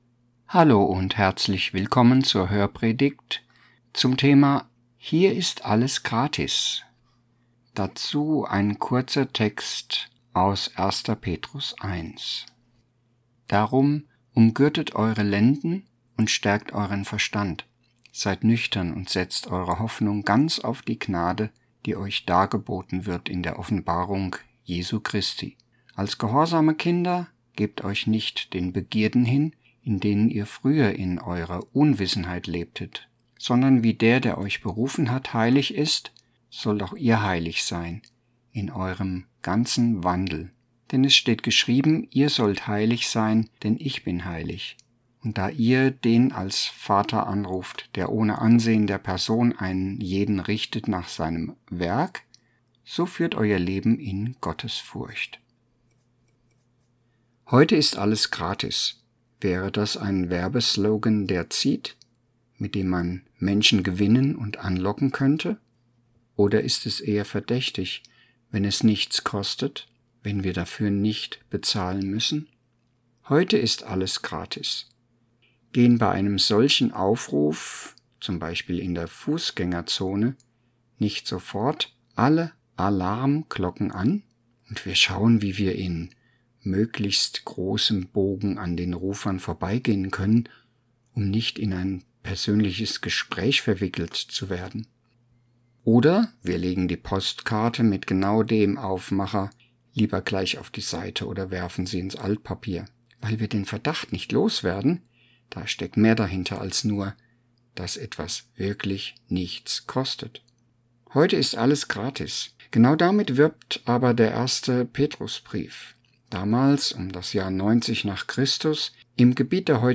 Hörpredigt-ZV-Februar_2025.mp3